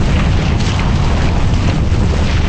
Quake1.ogg